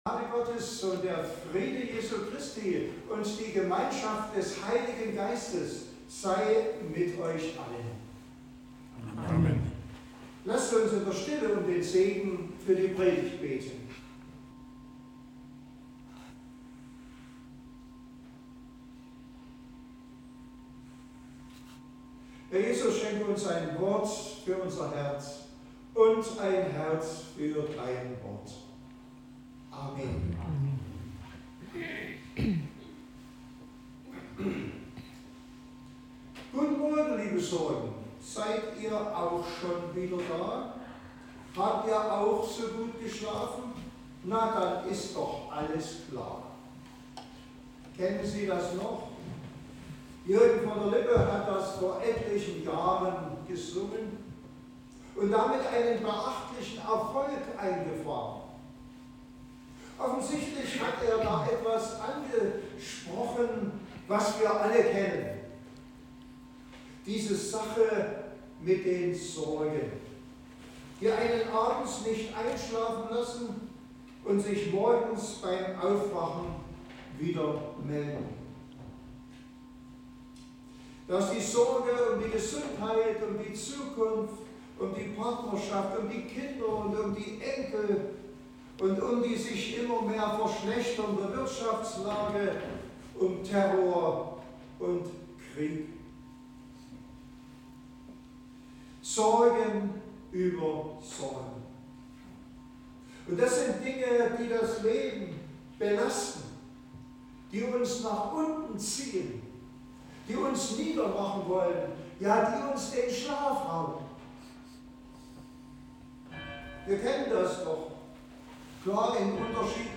Passage: 1.Petrus 5; 5b - 11 Gottesdienstart: Predigtgottesdienst Wildenau « Grund zur Dankbarkeit Beten